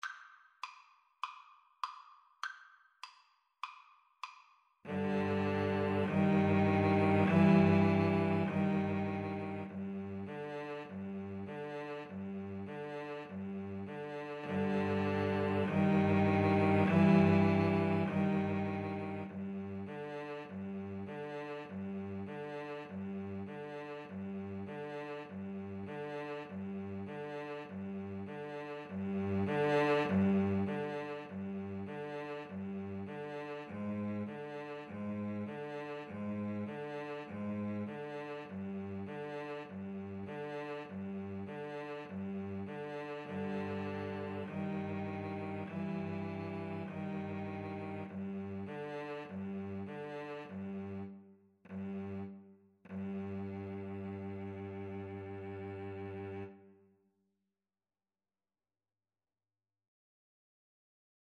A hint of James Bond and a hint of Egypt in this piece.
4/4 (View more 4/4 Music)
Jazz (View more Jazz Violin-Cello Duet Music)